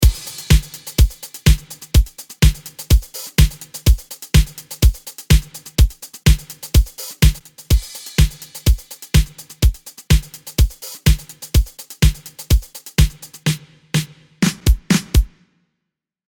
Das Hörbeispiel umfasst acht Takte. Zuerst hört man vier Takte lang den NI VC 160, danach ohne Umschaltpause das Logic Pro X Plug-in.